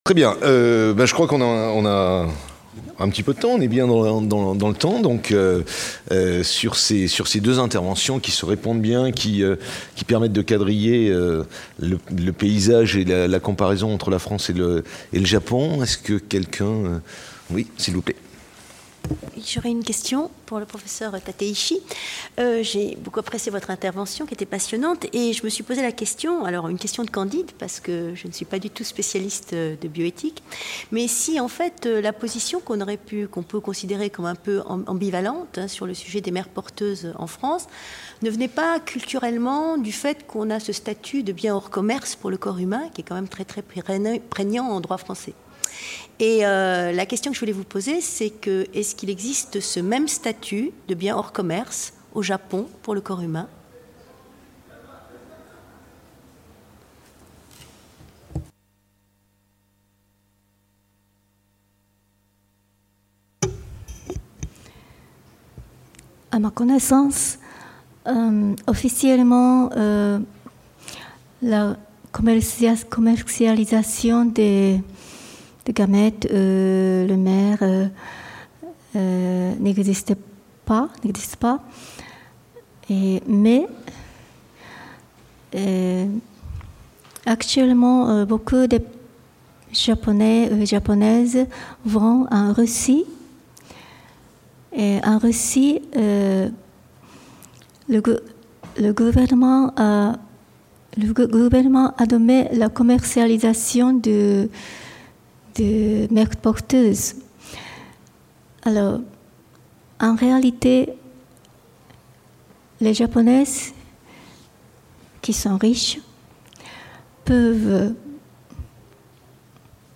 Colloque Nihon Europa, mardi 4 octobre 2016, discussion, seconde partie d'après-midi | Canal U